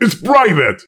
panelopen03.ogg